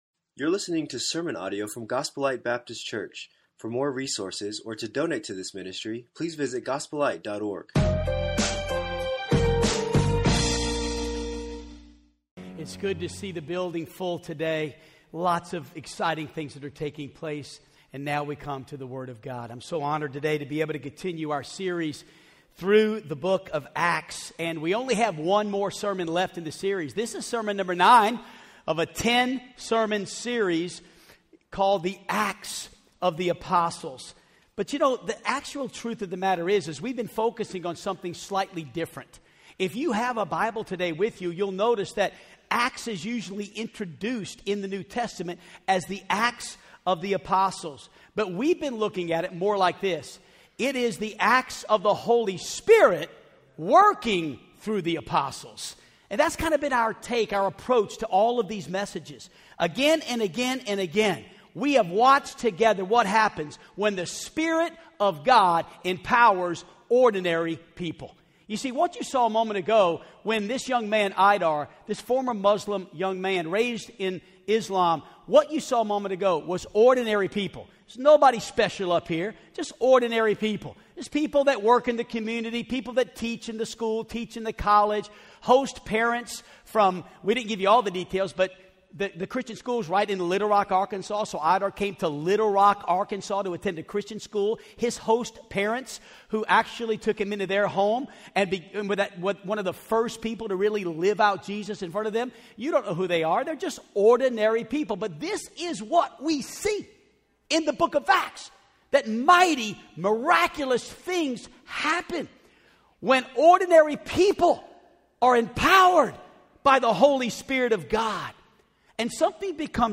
Acts of the Holy Spirit - Sermon 9